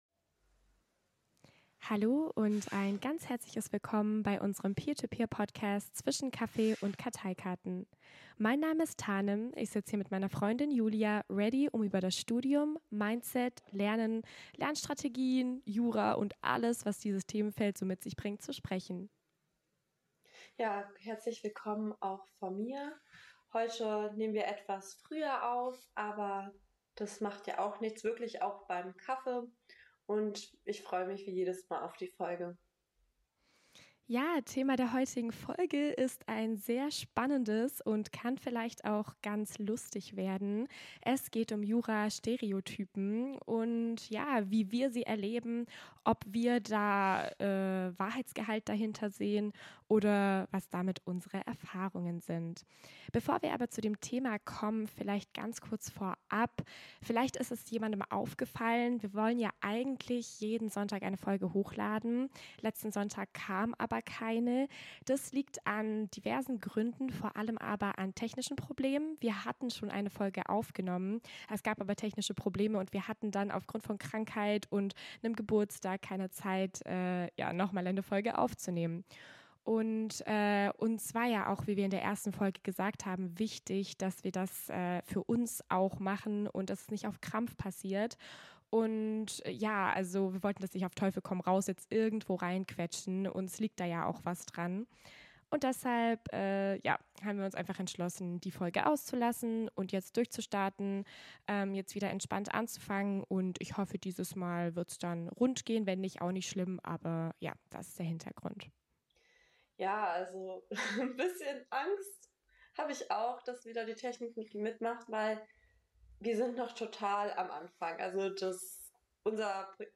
In dieser Folge nehmen wir die bekanntesten Jura Stereotypen unter die Lupe, vom Konkurrenzkampf bis zum Jura-Justus. Zwischen Kaffee, Chaos und kleinen Wahrheiten werfen wir einen Blick hinter die Fassade und erzählen euch, was wirklich dran ist. Ein augenzwinkernder Reality Check mit zwei Kaffeetassen und jeder Menge Meinung.